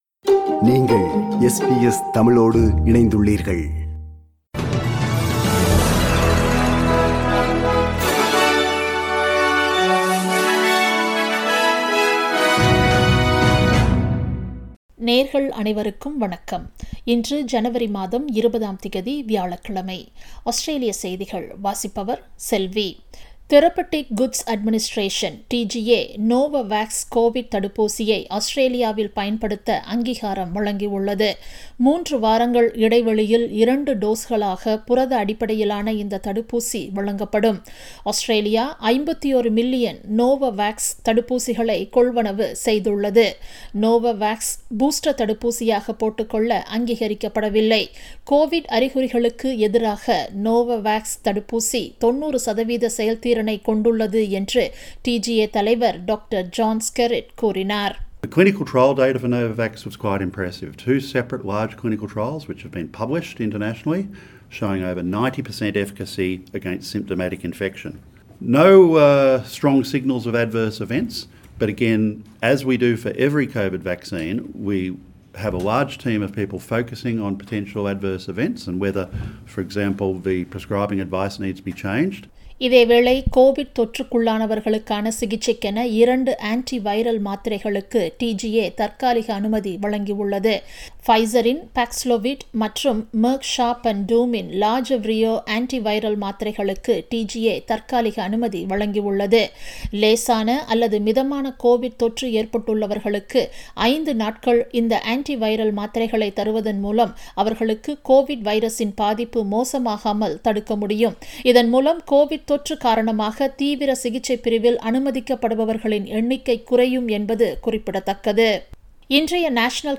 Australian news bulletin for Thursday 20 January 2022.